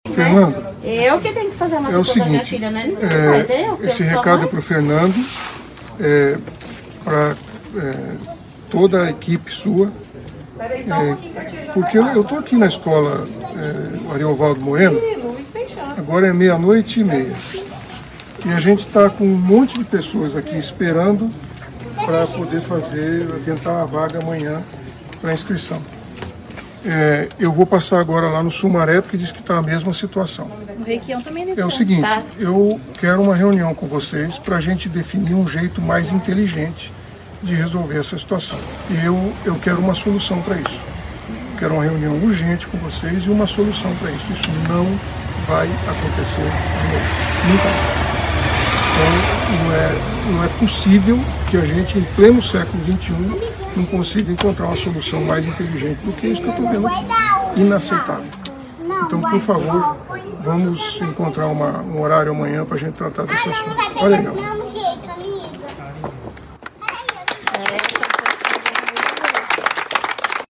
Ouça o trecho do vídeo em que o prefeito Silvio Barros entra em contato com o secretário de Educação Fernando Brambilla e marca uma reunião para buscar uma solução para as filas: